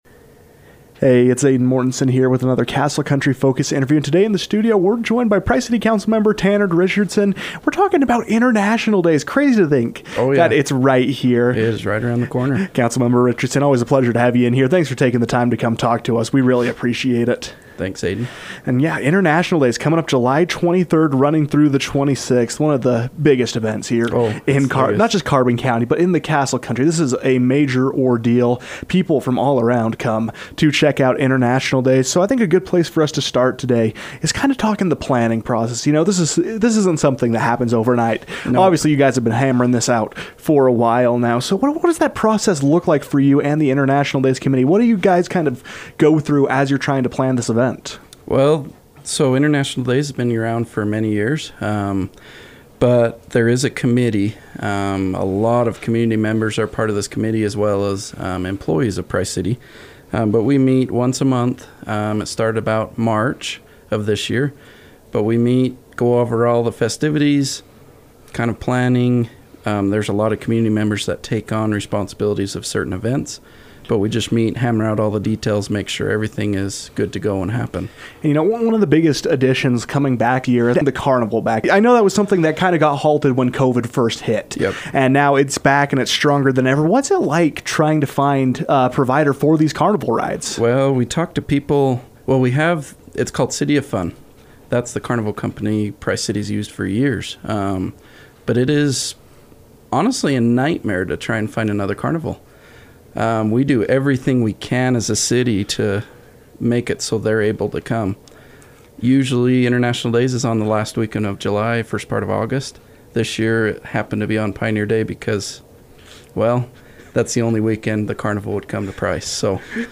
Price City council member Tanner Richardson joined the KOAL newsroom to discuss the behind-the-scenes planning of the event, as well as preview what’s in store for attendees.